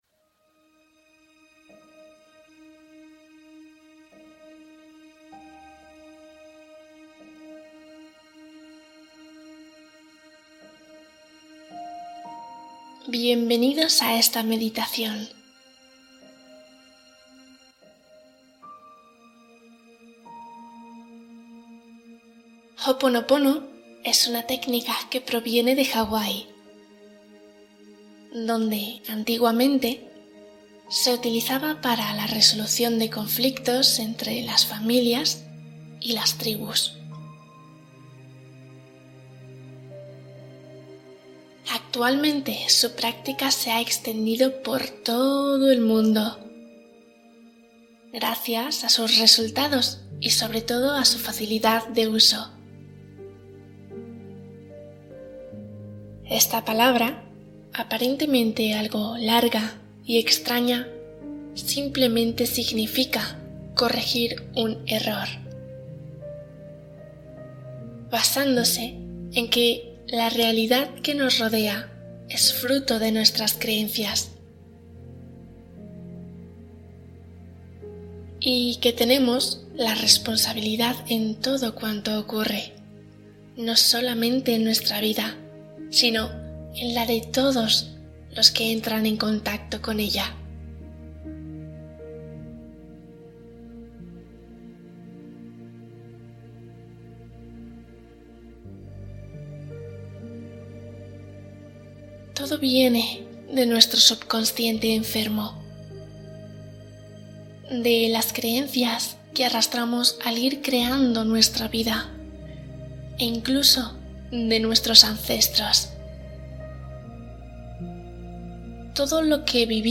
Ho’oponopono | Meditación para sanar el corazón